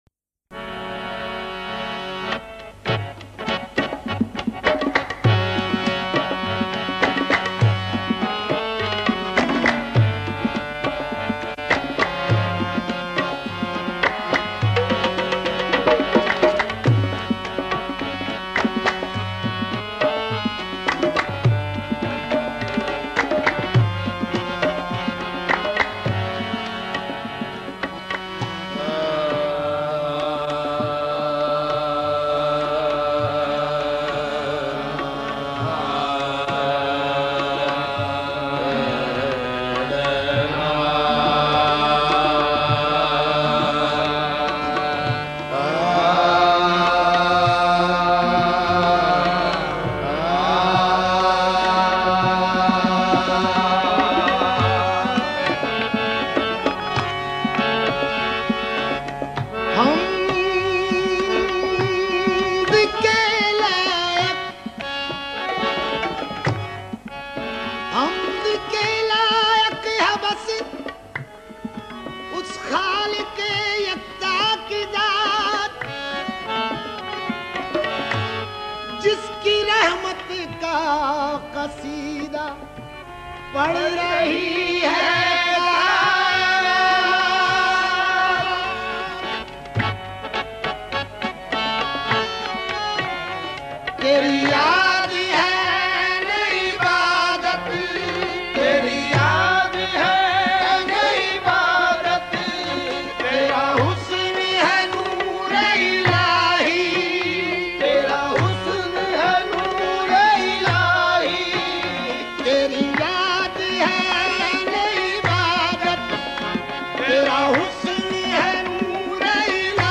Download MP3 Worlds Largest Collection of Qawwali